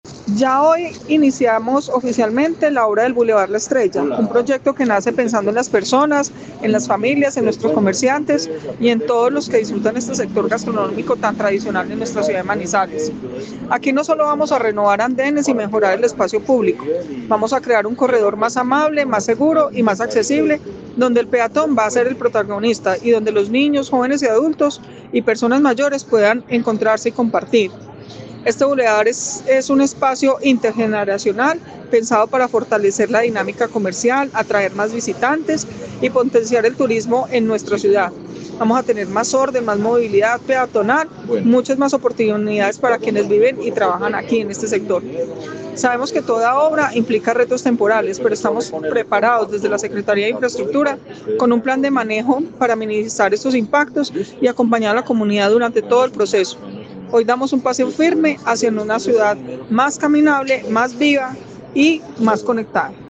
Audio: declaraciones de la secretaria de Infraestructura, Claudia Marcela Cardona Mejía